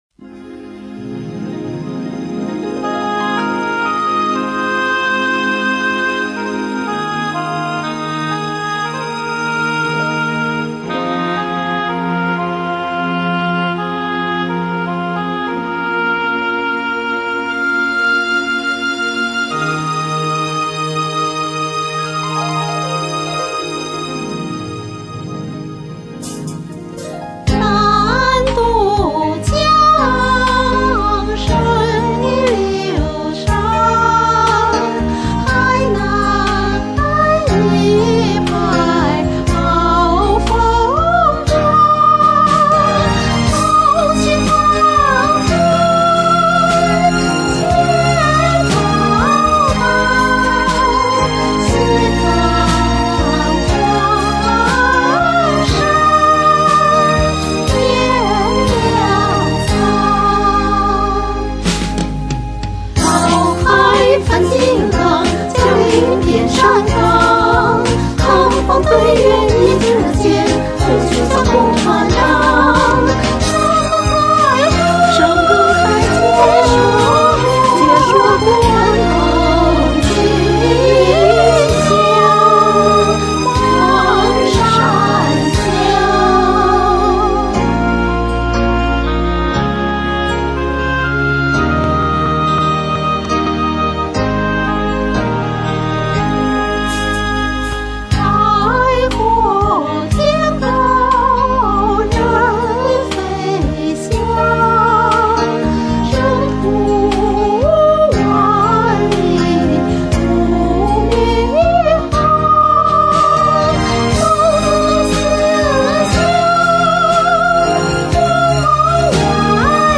现在用的这个伴奏速度正好，可是杂音太大。
这歌旋律好听